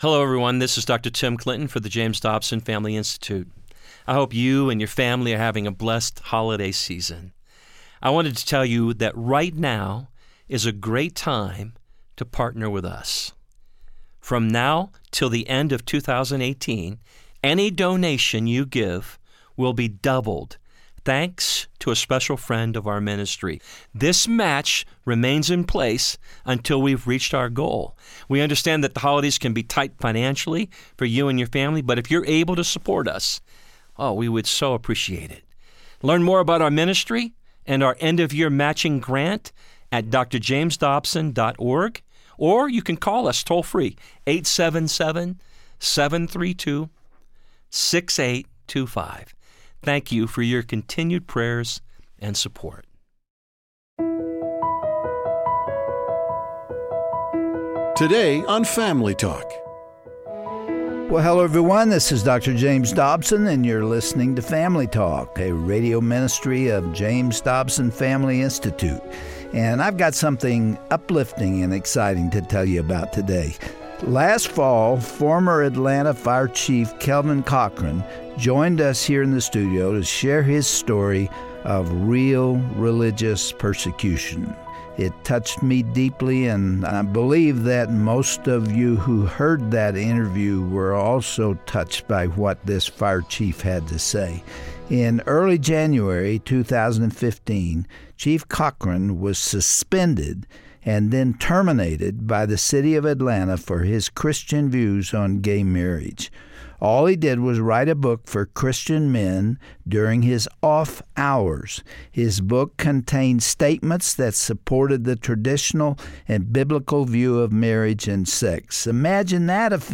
On this edition of Family Talk, Dr. Dobson provides an update to the religious liberty case involving former Atlanta Fire Chief, Kelvin Cochran. You'll hear Dr. Dobson's interview from last fall with Chief Cochran, and the developments in his lawsuit against the city of Atlanta.